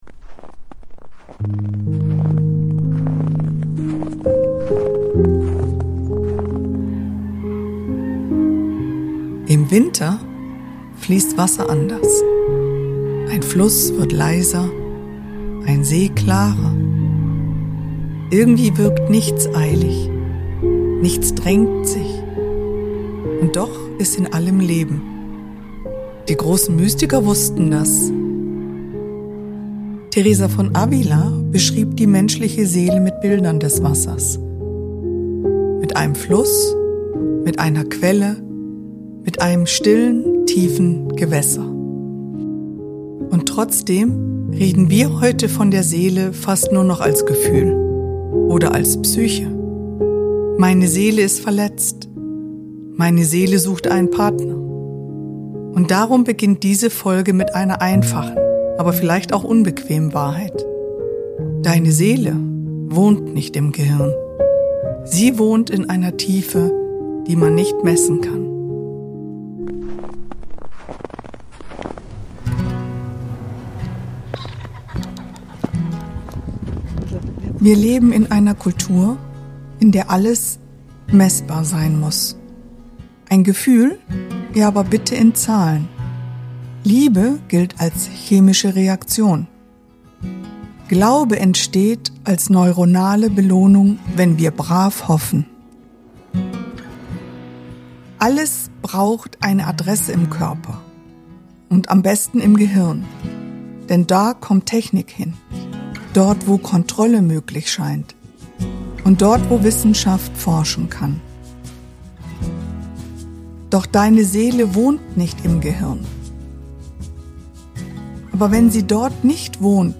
Diskurs – sondern ein kontemplativer Raum. 7 Minuten mit Slow